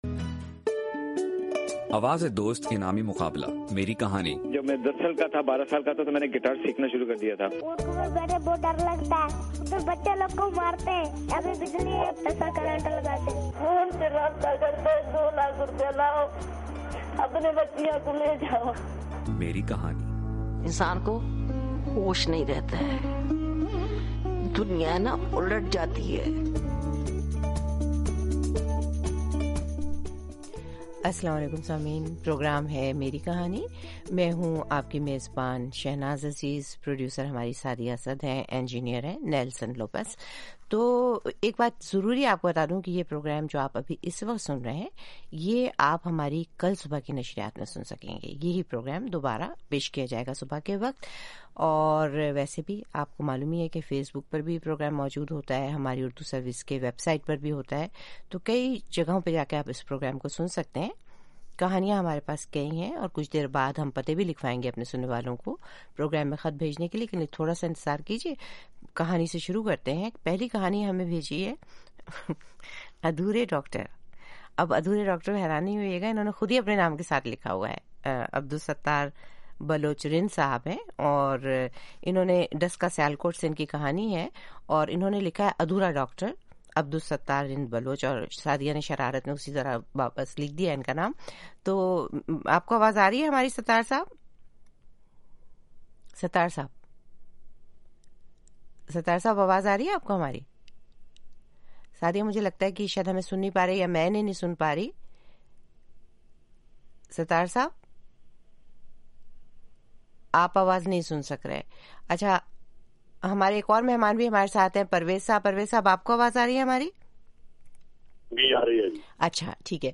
اس پروگرام میں تجزیہ کار اور ماہرین سیاسی، معاشی، سماجی، ثقافتی، ادبی اور دوسرے موضوعات پر تفصیل سے روشنی ڈالتے ہیں۔